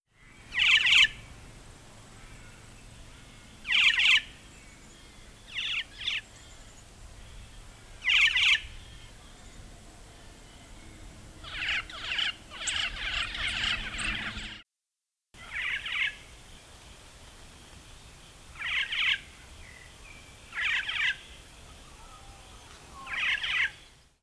Black-faced Cuckoo-shrike:
great recordings – with sheep in the background!
black-faced-cuckoo-shrike.mp3